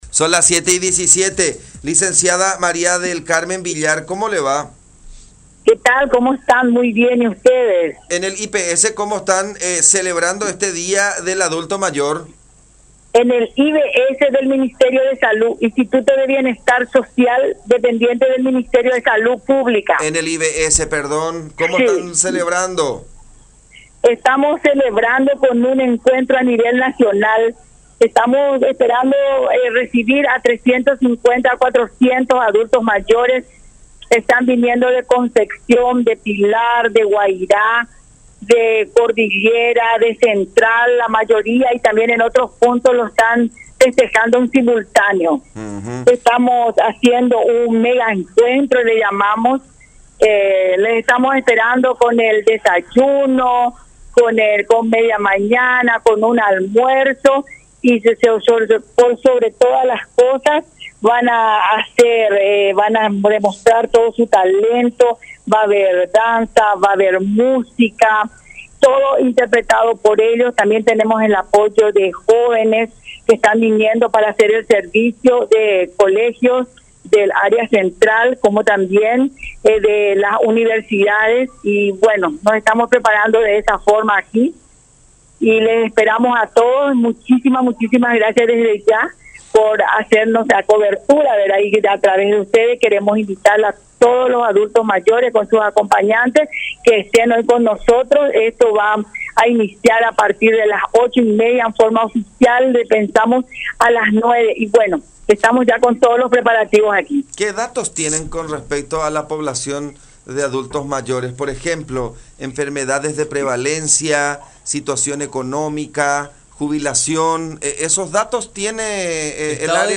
11-Lic.-María-del-Carmen-Villar-Directora-del-Instituto-de-Bienestar-Social-sobre-el-Día-del-Adulto-Mayor.mp3